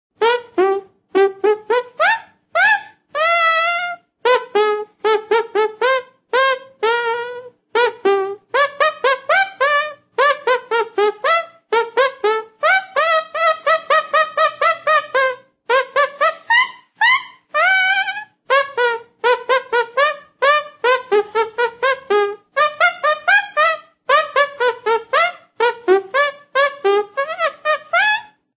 в переложении для ладоней